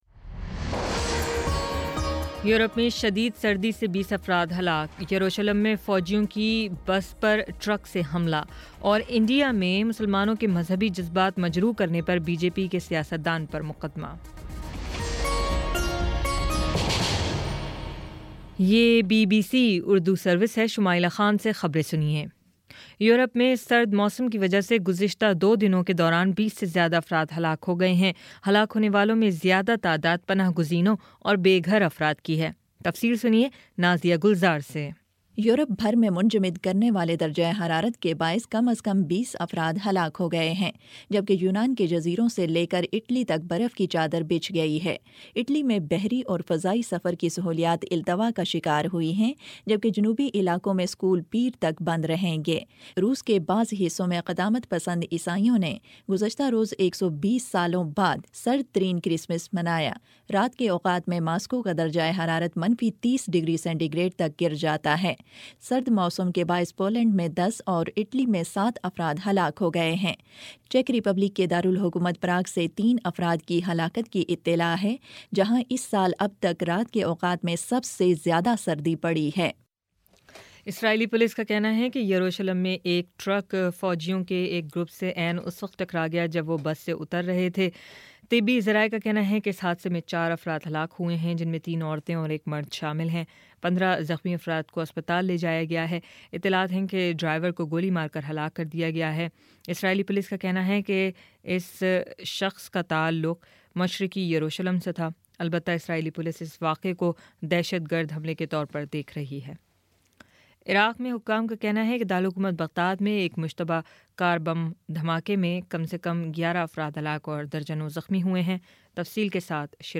جنوری 08 : شام سات بجے کا نیوز بُلیٹن